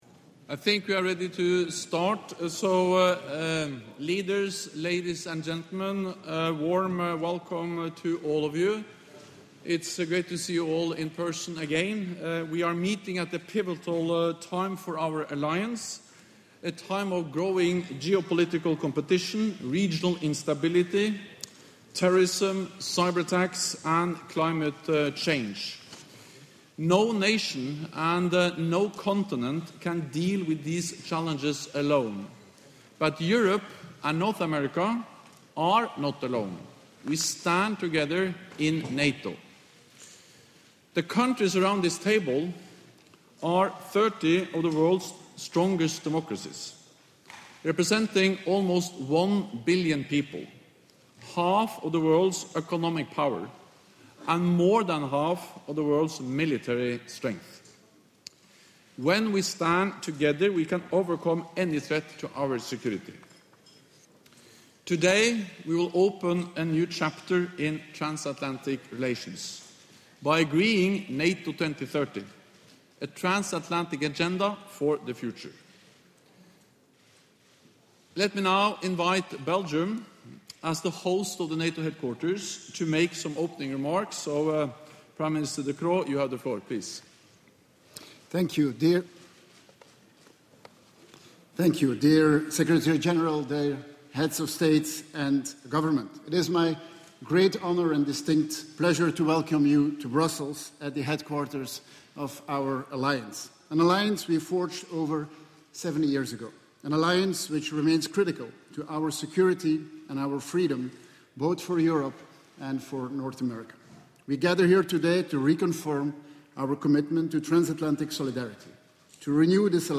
Remarks by NATO Secretary General Jens Stoltenberg at the ''NATO 2030 at Brussels Forum'' event
Opening speech by the NATO Secretary General at NATO 2030 @ BRUSSELS FORUM